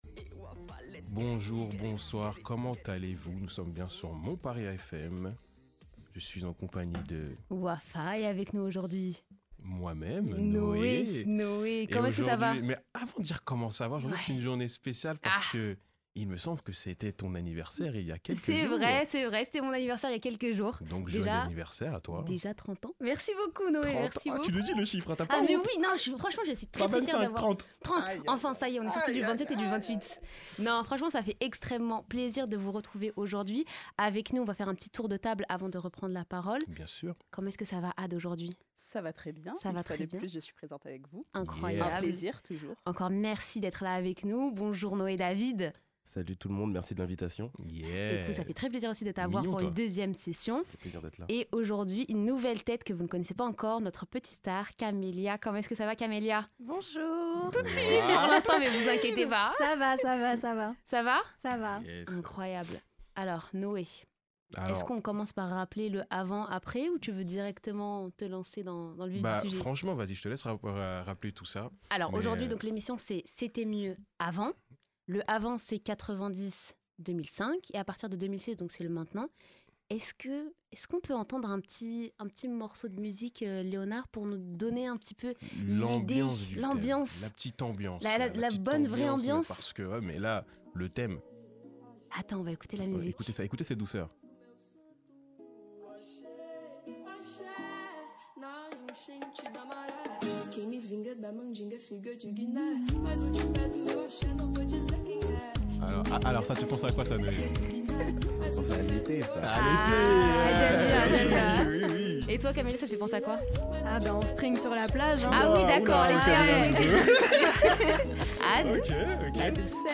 Aujourd’hui, nous aborderons « le voyage» avec nos animateurs